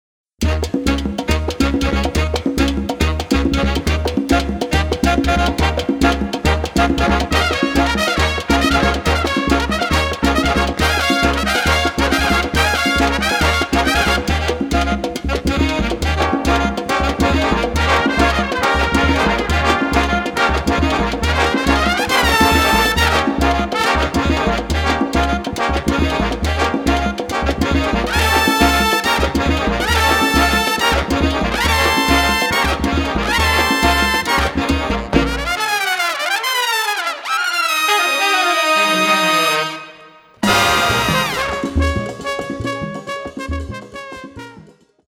Category: big band
Style: mambo
Instrumentation: big band (4-4-5, rhythm (4)